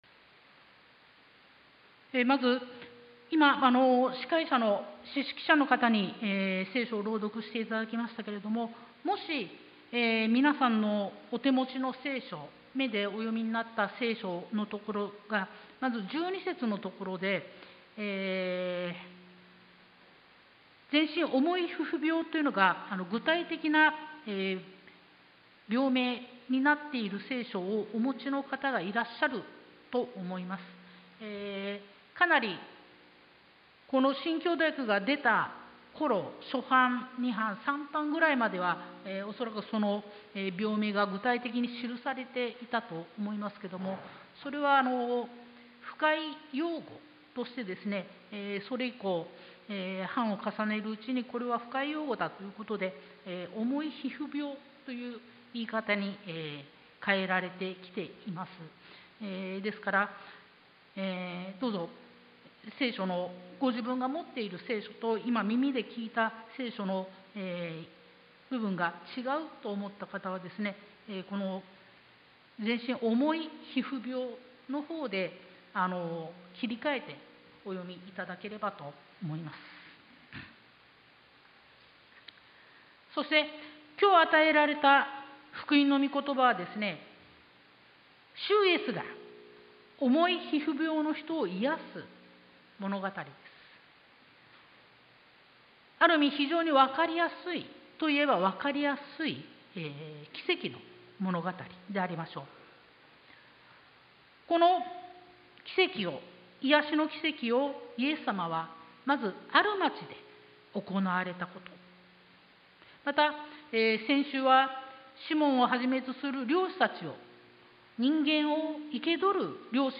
sermon-2022-07-10